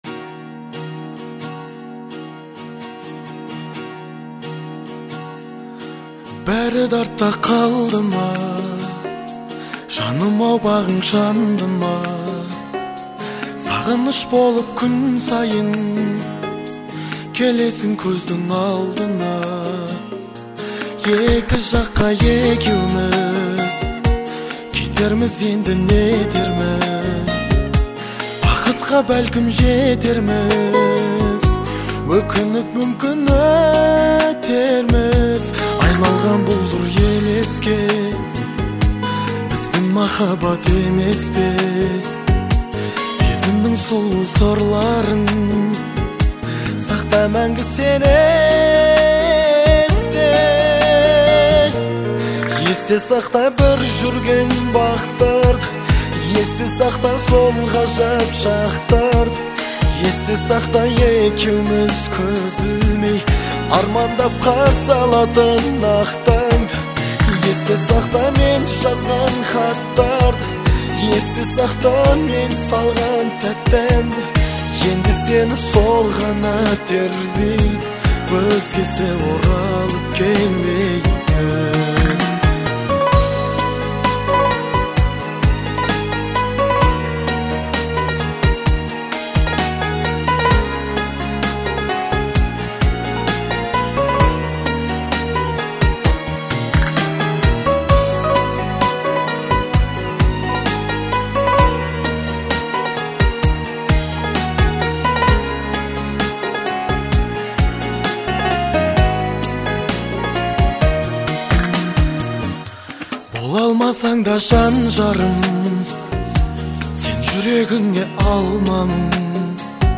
проникновенная песня